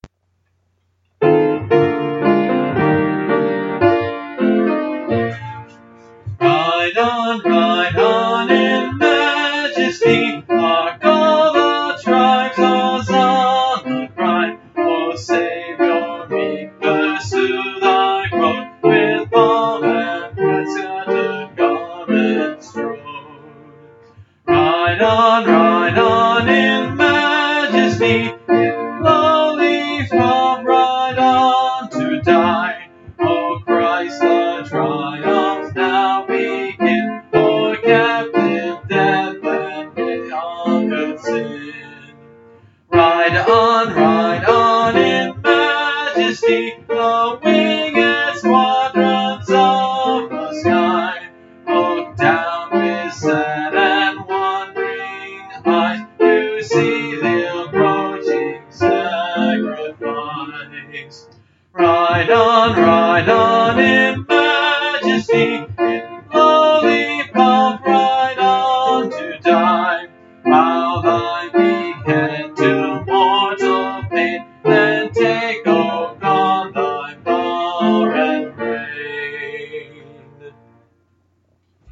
(Part of a series singing through the hymnbook I grew up with: Great Hymns of the Faith)
This is a hymn I don’t even remember singing.